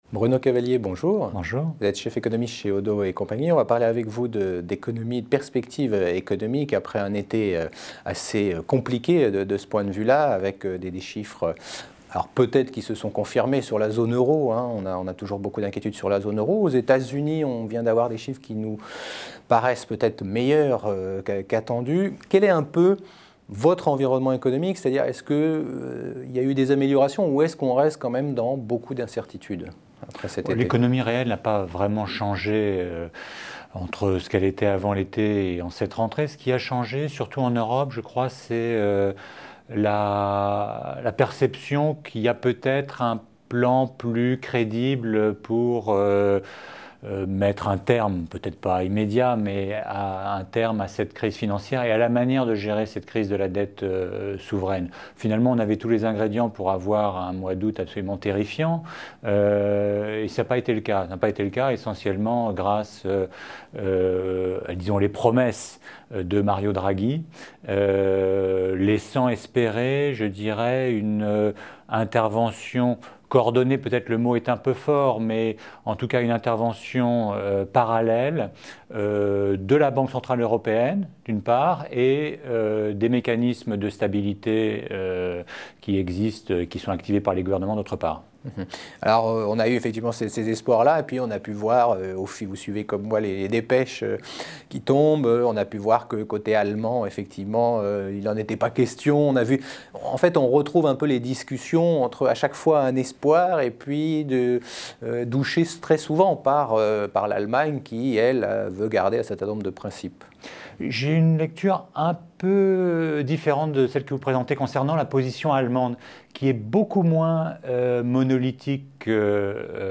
Actualité économique : Interview